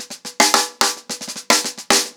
TheQuest-110BPM.11.wav